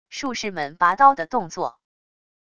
术士们拔刀的动作wav音频